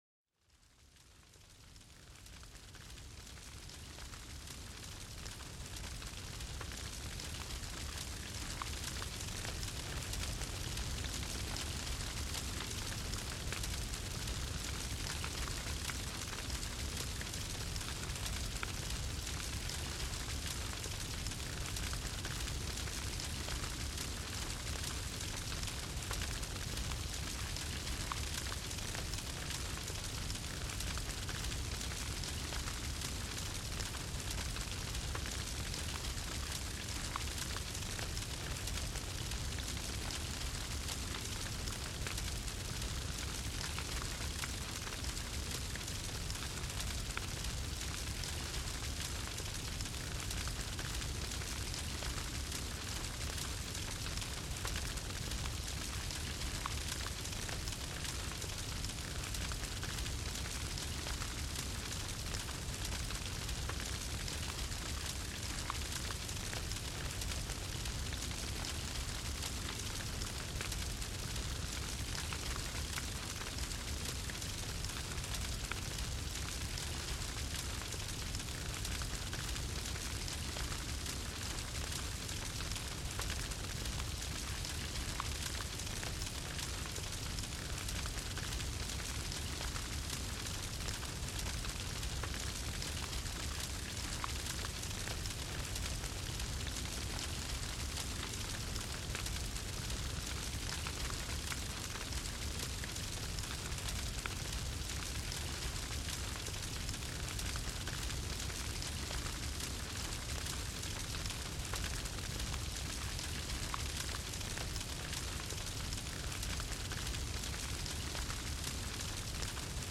Déjate arrullar por el suave crepitar de la fogata para una relajación total y un sueño profundo
El sonido relajante de la fogata evoca calidez y serenidad en el corazón de la naturaleza.
Cada episodio te sumerge en una atmósfera tranquila y natural.